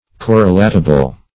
correlatable - definition of correlatable - synonyms, pronunciation, spelling from Free Dictionary
Search Result for " correlatable" : The Collaborative International Dictionary of English v.0.48: Correlatable \Cor`re*lat"a*ble\ (k3r`r?-l?t"?-b'l), a. Such as can be correlated; as, correlatable phenomena.